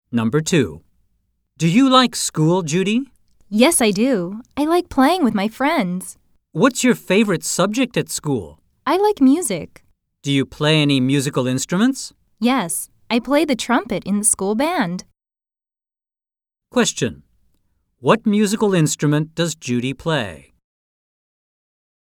Listening Test はBook 1,2,3が簡単すぎるという声を受け、natural speedで約３０分の内容にしました。
■Listening Test 音声サンプル■